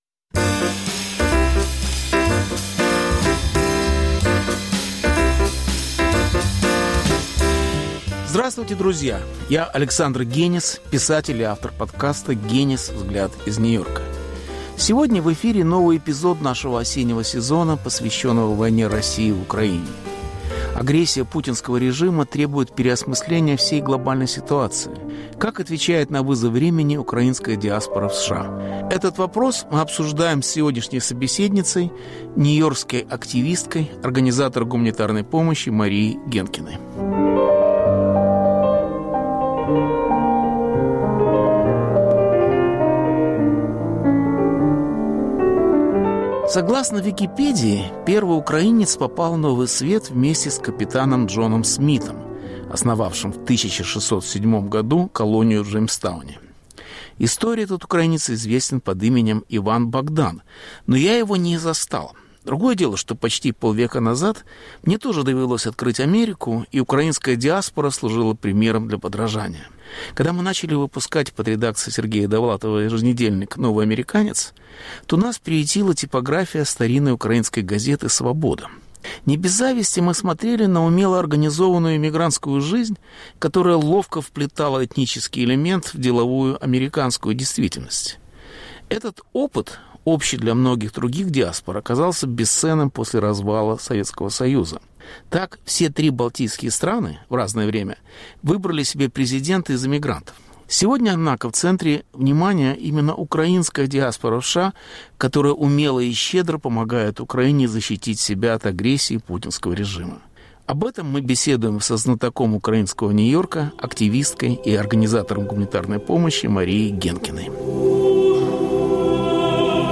В центре внимания украинская диаспора в США, которая умно и щедро помогает Украине защитить себя от агрессии путинского режима. Беседа